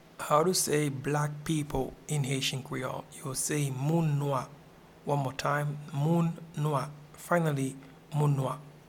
Pronunciation and Transcript:
Black-people-in-Haitian-Creole-Moun-nwa.mp3